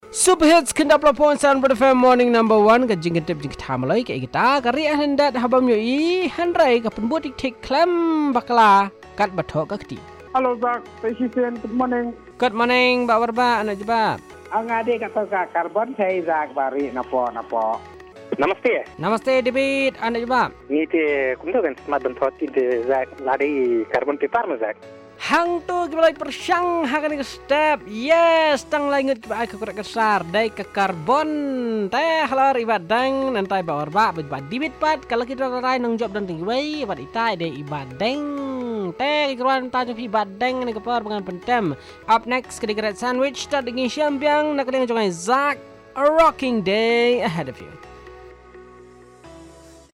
# callers